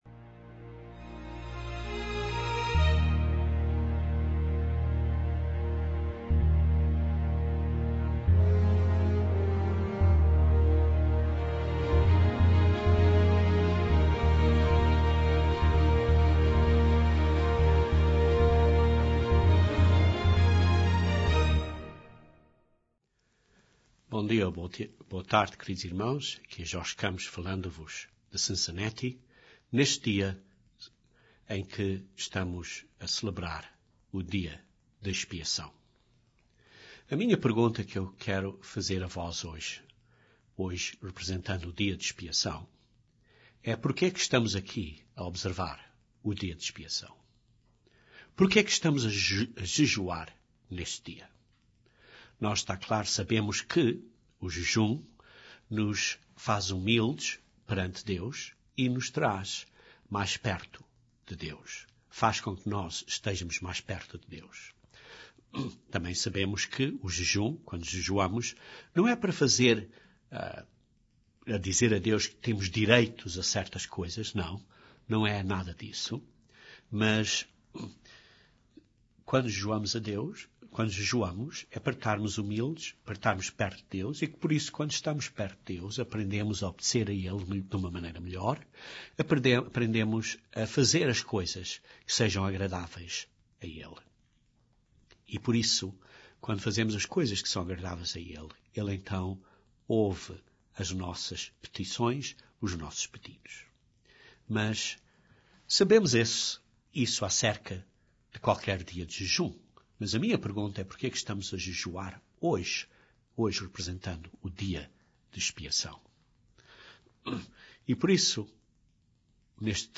Mas por quê que jejuamos neste dia específico? Este sermão aborda este assunto.